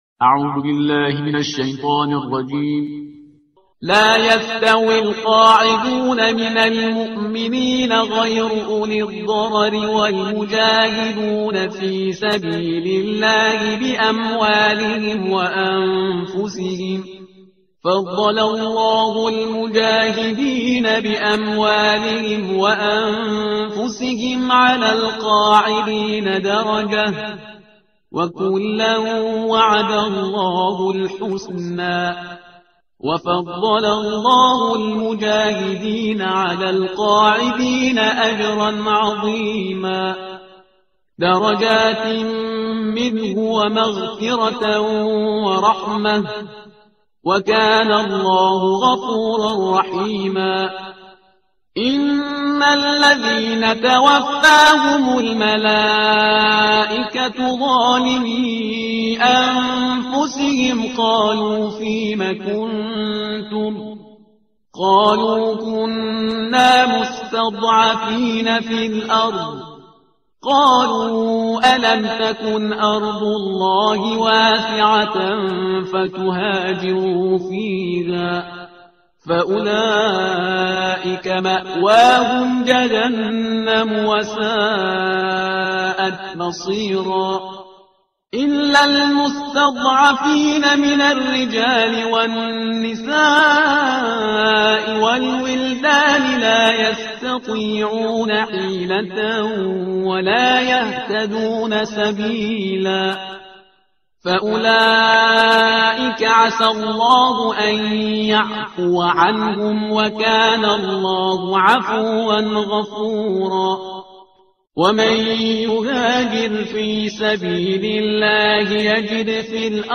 ترتیل صفحه 94 قرآن